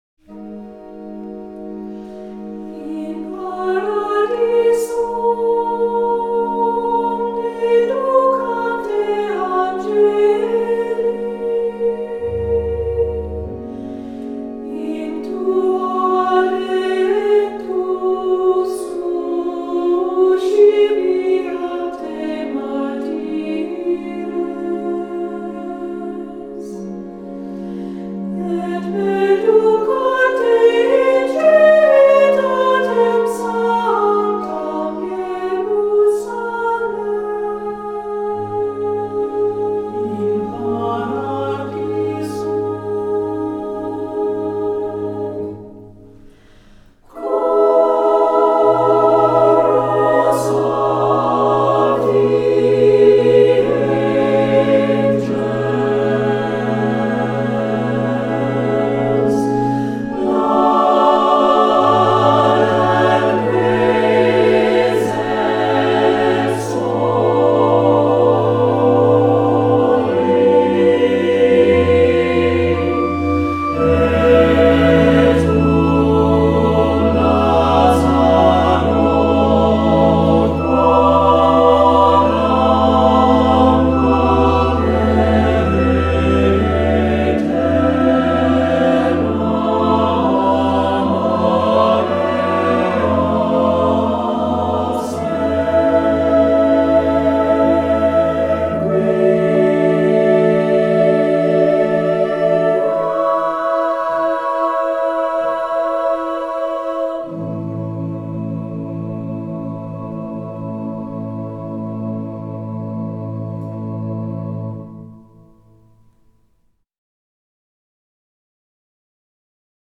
Voicing: SATB or Two-Part